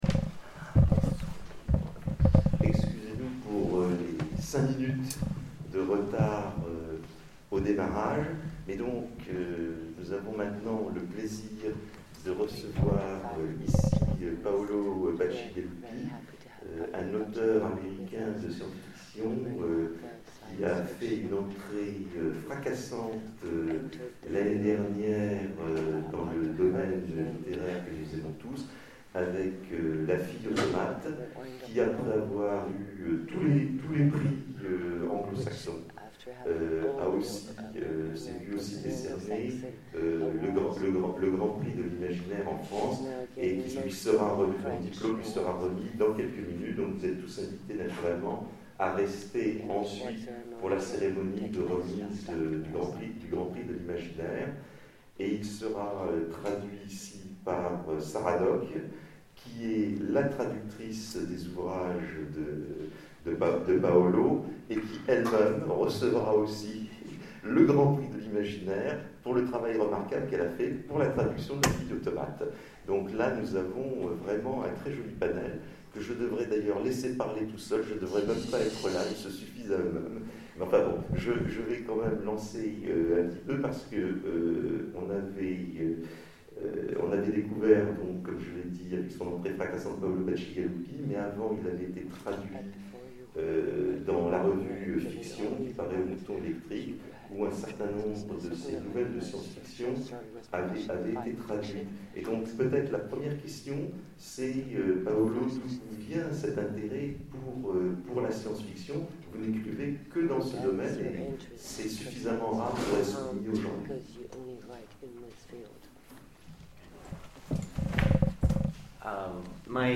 Etonnants Voyageurs 2013 : Conférence L'univers de Paolo Bacigalupi
Conférence
Rencontre avec un auteur